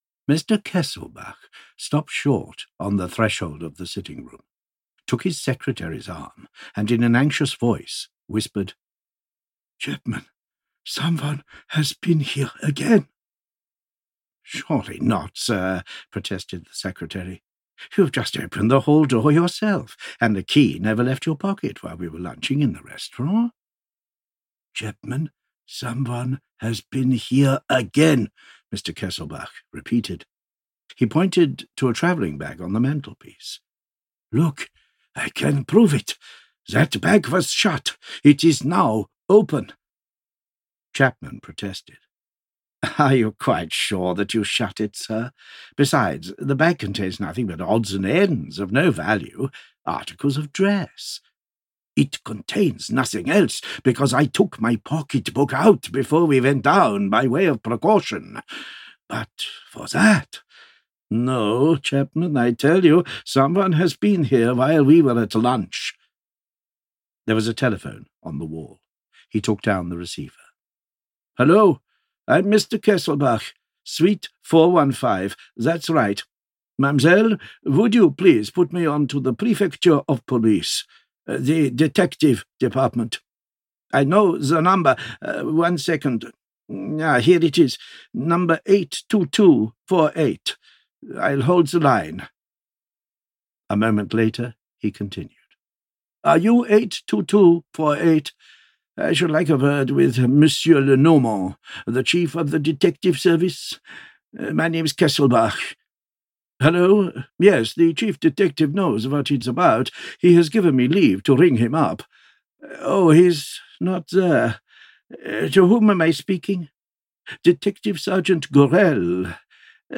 813 audiokniha
Ukázka z knihy